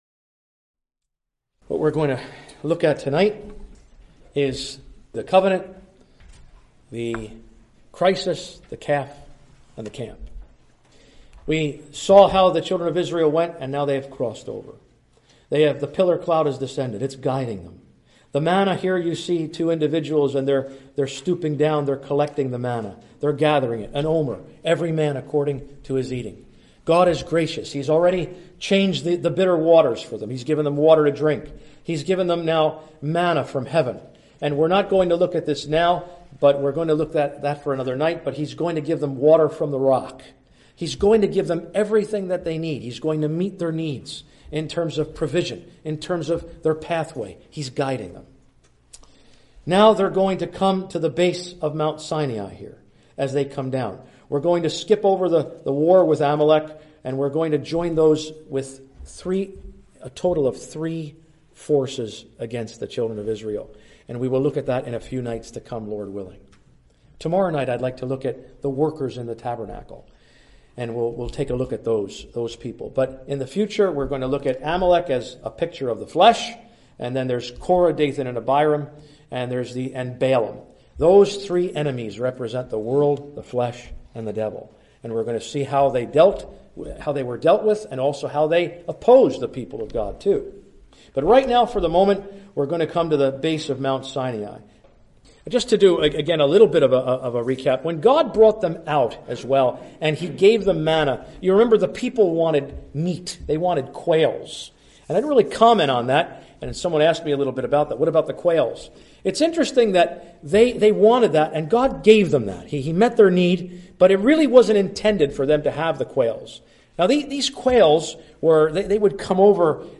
Readings from Exodus 19, 20, 25, 32, 34. (Recorded in Marion Gospel Hall, Iowa, USA)